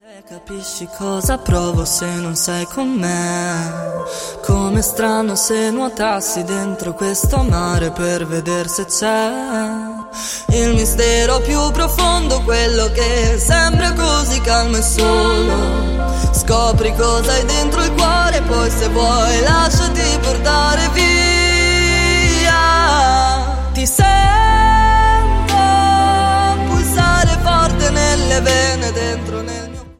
POP  (02.42)